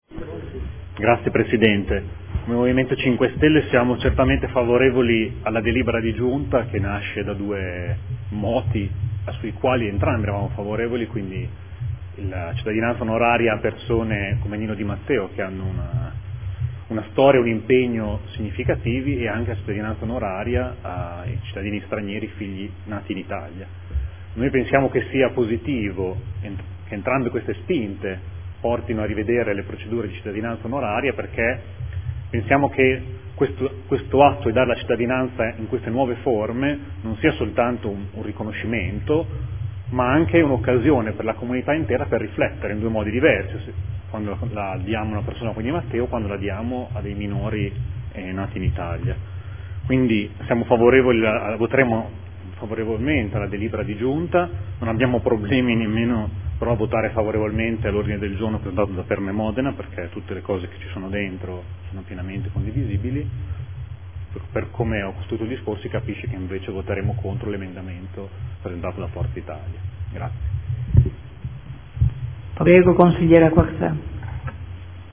Mario Bussetti — Sito Audio Consiglio Comunale